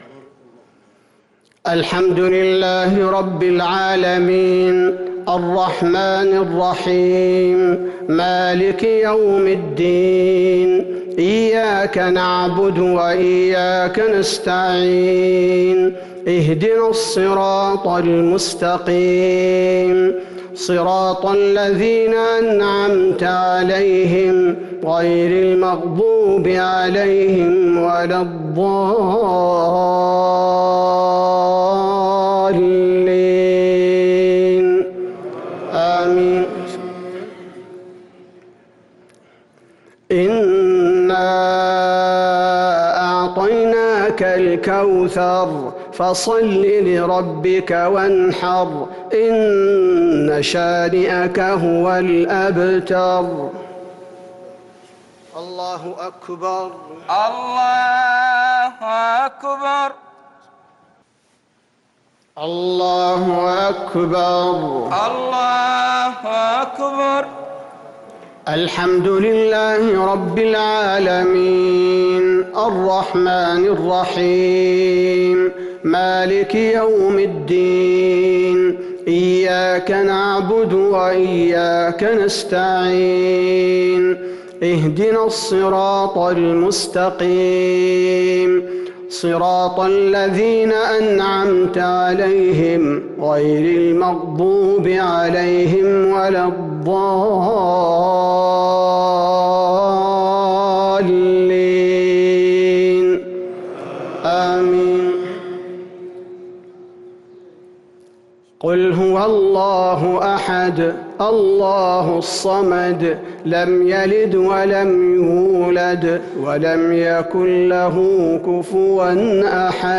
صلاة الجمعة 4-6-1443هـ سورتي الكوثر و الإخلاص | Jumu’ah prayer Surat al-Kauthar & al-Ikhlas 7-1-2022 > 1443 🕌 > الفروض - تلاوات الحرمين